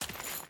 Dirt Chain Walk 1.ogg